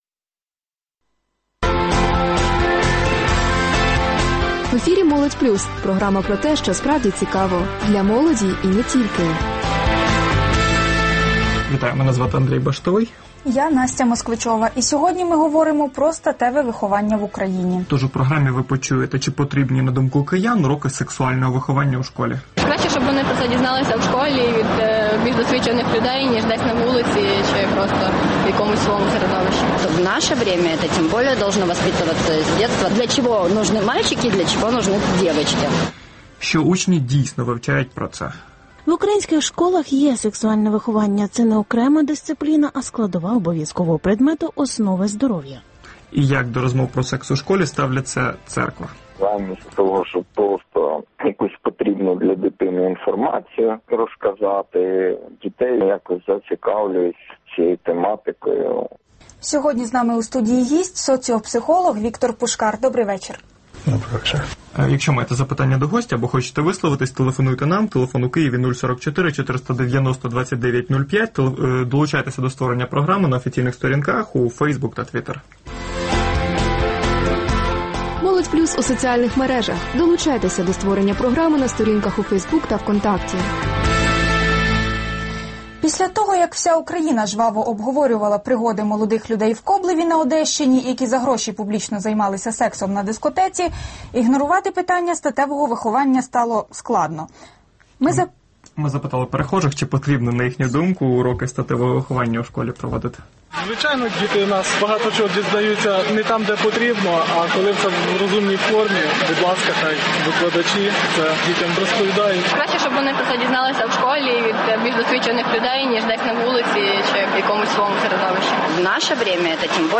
У студії Оксана Сироїд, директор Української правничої фундації, учасниця ініціативи Реанімаційни пакет реформ.